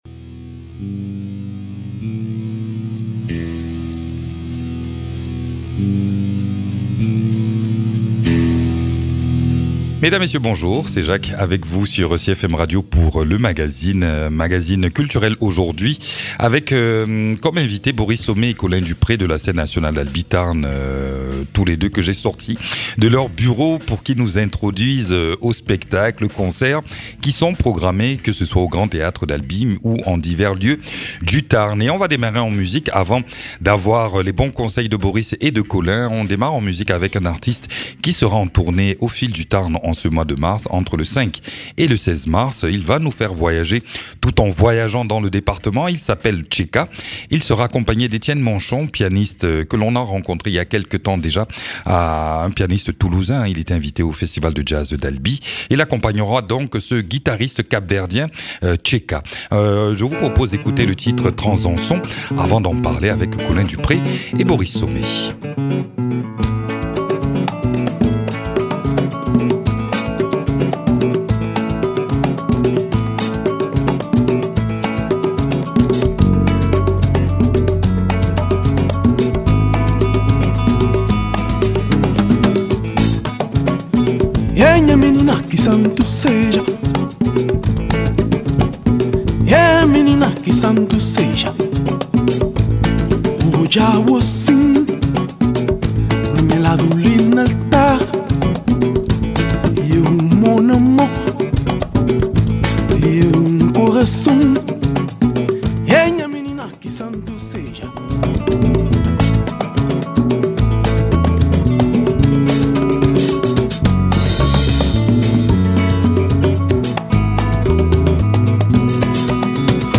Nous retrouvons nos 2 invités habituels pour un tour d’horizon des spectacles vivants qui sont proposés dans le Tarn en ce mois de mars.